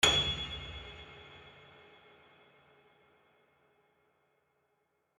piano-sounds-dev
gs6.mp3